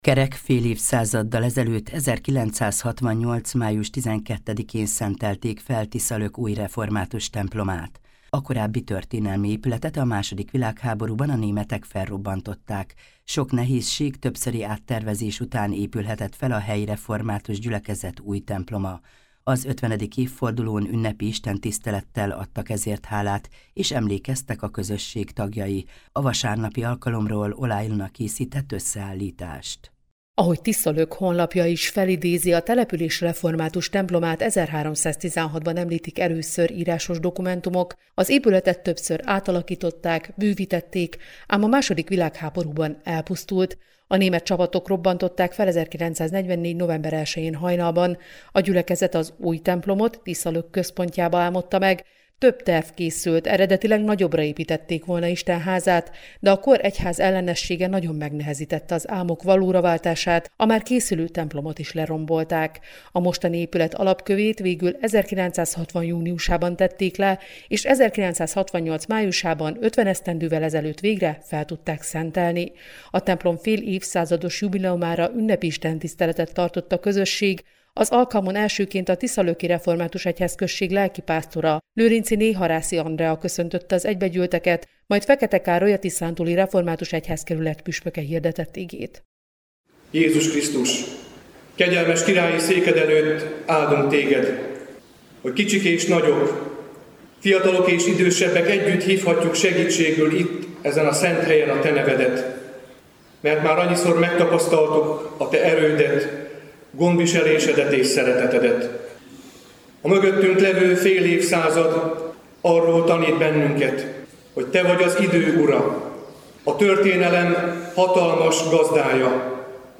Az Igét dr. Fekete Károly, a Tiszántúli Református Egyházkerület püspöke hirdette ApCsel 1,4-8 alapján „váróvasárnapon”, amely a mennybemenetel és pünkösd között jelentette a várakozást a Szentlélek kiáradásáért.